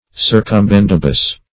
Search Result for " circumbendibus" : The Collaborative International Dictionary of English v.0.48: Circumbendibus \Cir`cum*bend"i*bus\, n. A roundabout or indirect way.